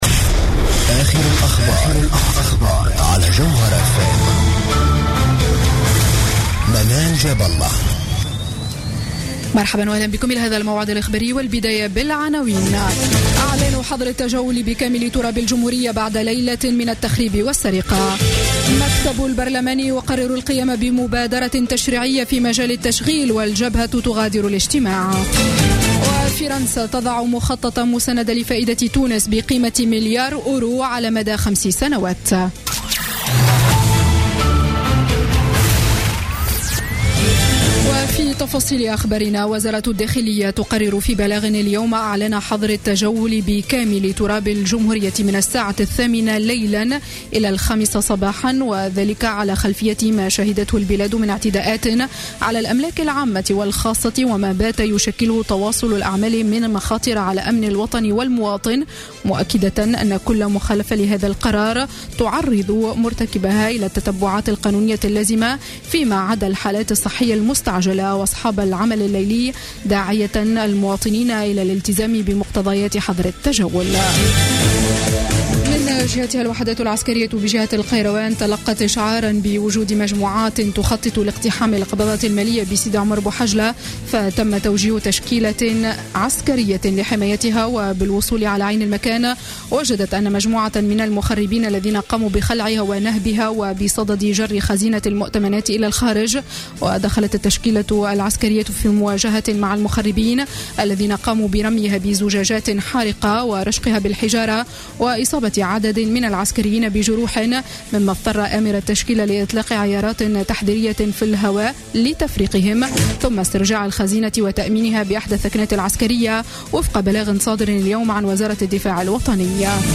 نشرة أخبار السابعة مساء ليوم الجمعة 22 جانفي 2016